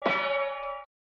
Pan Bang
pan-bang.mp3